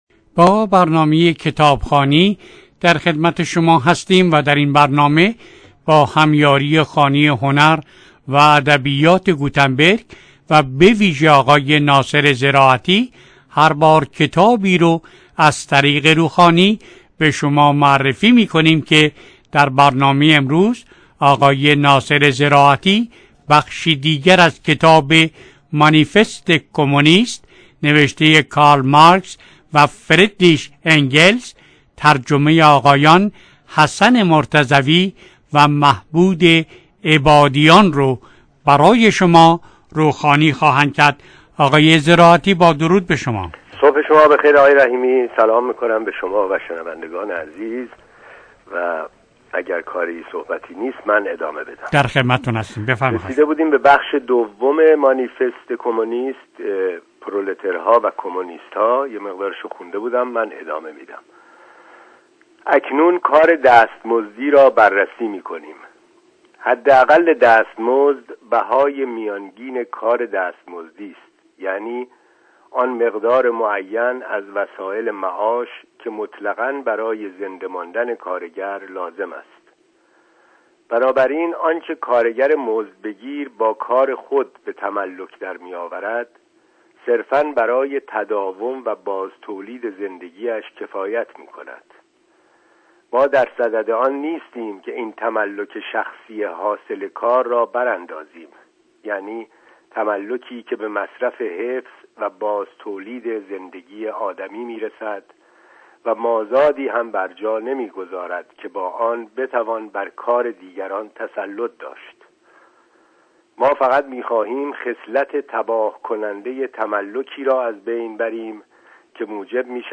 در برنامه کتابخوانی رادیو سپهر روخوانی شده و به یادگار در وبسایت رادیو پیام قرار می گیرد.